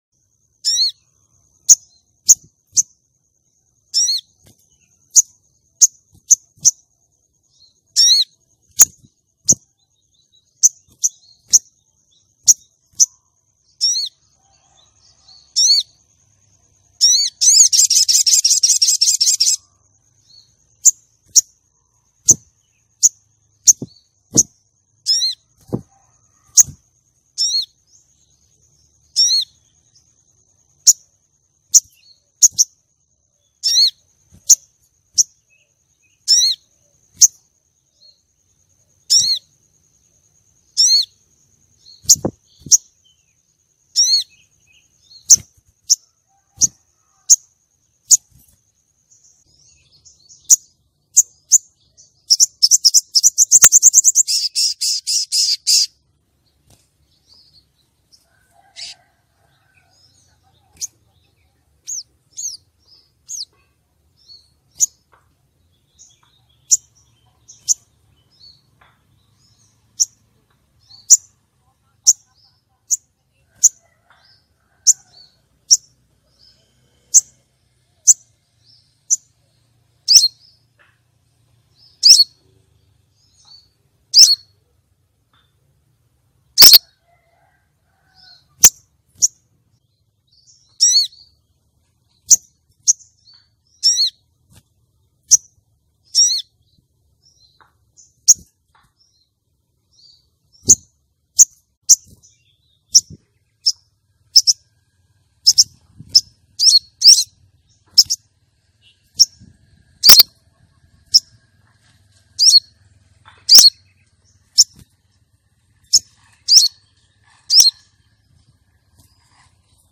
Suara Burung Sogon Betina
Kategori: Suara burung
suara-burung-sogon-betina-id-www_tiengdong_com.mp3